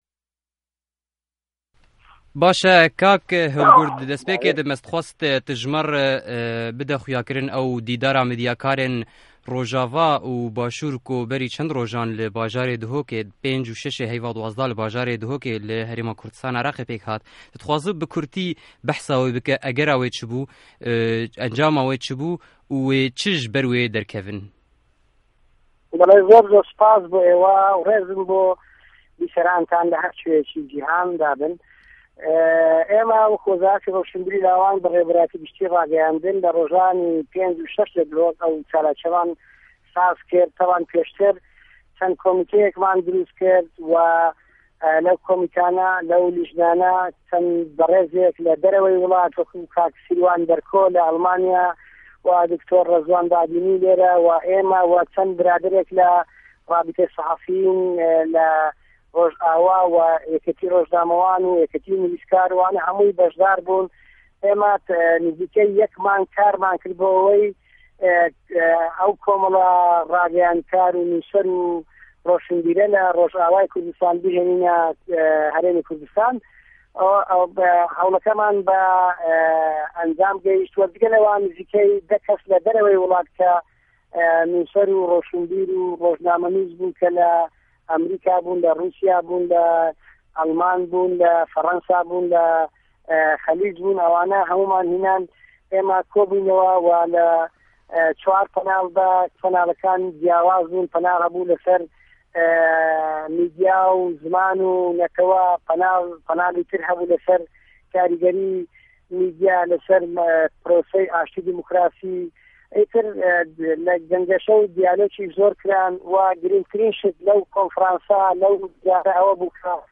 Hevpeyvîn ligel Helgurd Cundyanî